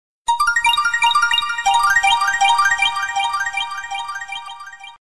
Рингтоны » На SMS